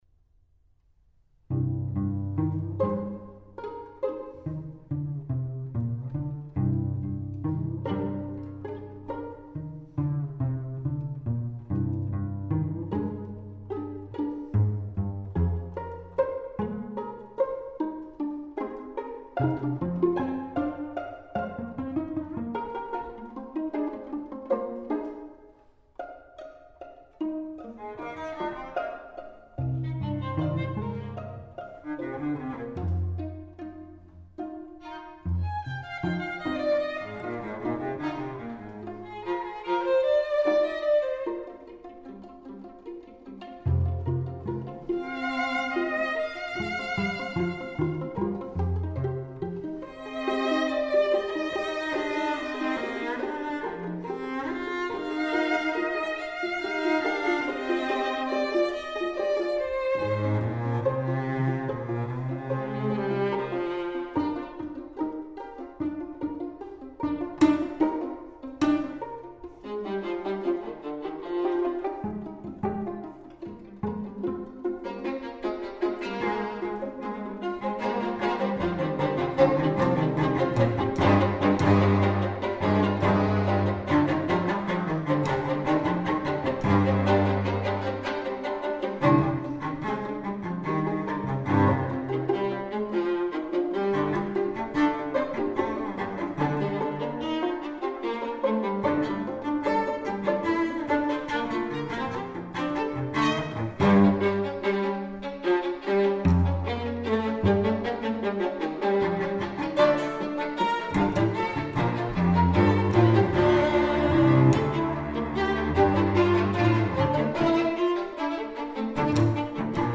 nhạc hòa tấu
nhóm tứ tấu đàn dây (2 violins, viola, và cello), do chính tác giả và các bạn nhạc sĩ trình bày và thu âm.